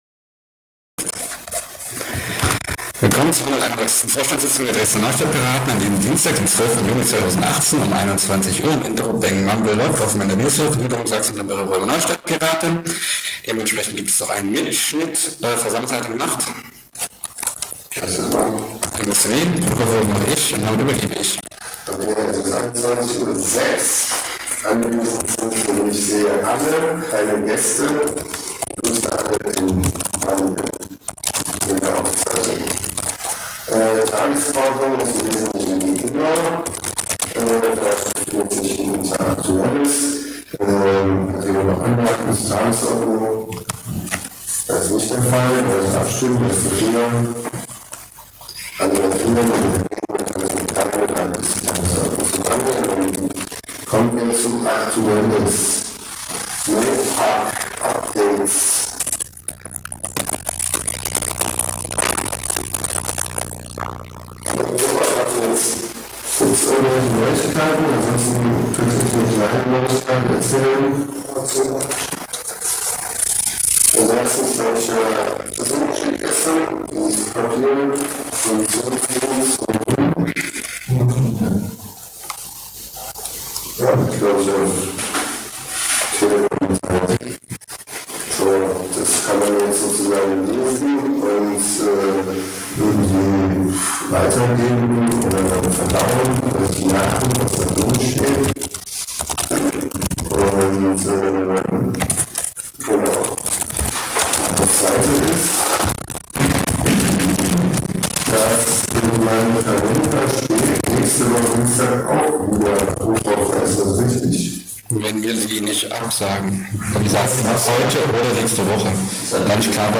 131. Vorstandssitzung der Dresdner Neustadtpiraten Dienstag, 12. Juni 2018, um 21:00 Uhr @ Interrobang
Mumble: NRW-Server, Gliederungen, Sachsen, temporäre Räume, Neustadtpiraten